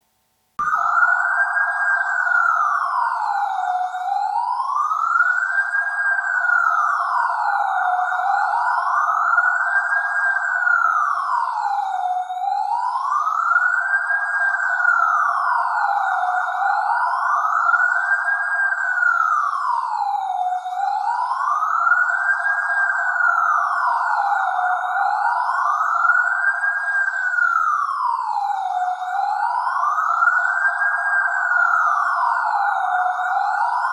Police Sirens